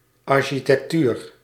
Ääntäminen
IPA: /aʁ.ʃi.tɛk.tyʁ/